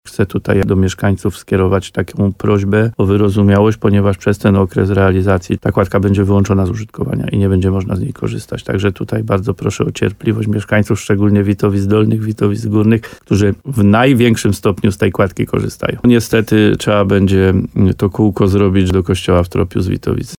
Będzie utrudnienie i niestety, aby dojechać do kościoła w Tropiu, to trzeba będzie to kółko zrobić – mówił wójt gminy Łososina Dolna Adam Wolak.